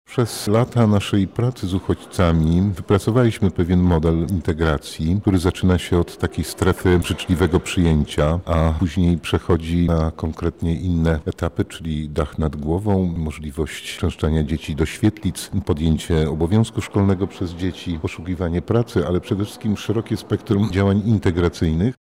Dziś podczas konferencji Centrum Wolontariatu rozmawialiśmy o tym, jak jednoczyć się z uchodźcami.